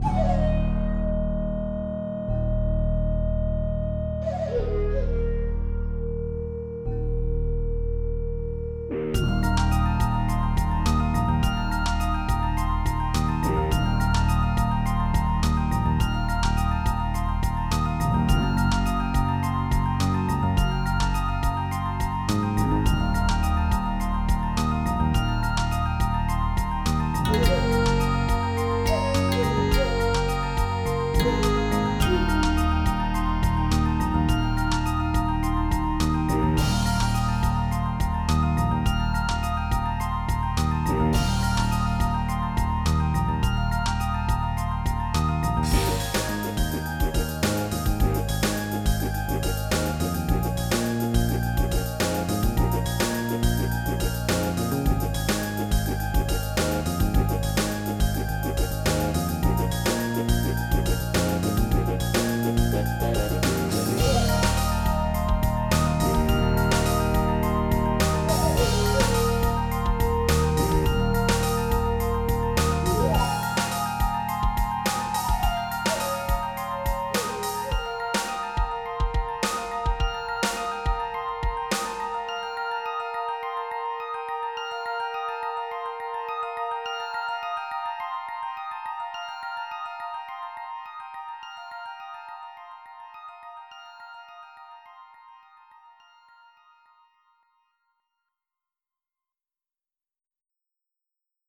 ROCK
MIDI Music File